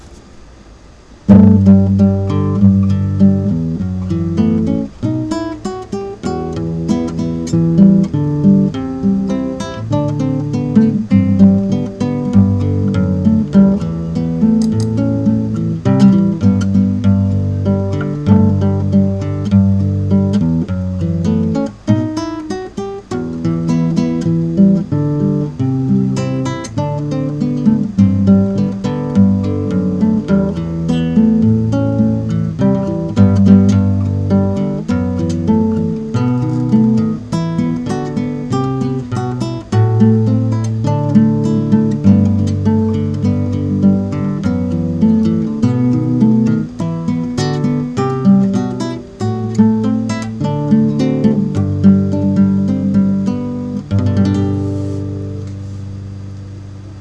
(חוץ מהאיכות הקלטה, שנגד זה אין לי ממש מה לעשות...)
זה קאבר